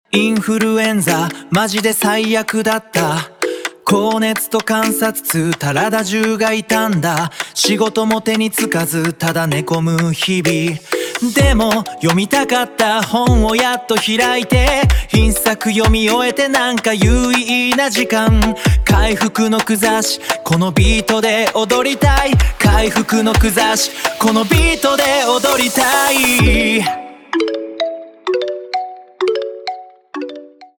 テキストで指示を出すだけで、歌詞の書き下ろしからメロディ、歌声の生成までをわずか数十秒で行なってくれます。
スタイル レゲトン
ただ、日本語としての発音に違和感があるところや、何と言っているのか聞き取れないところもありました。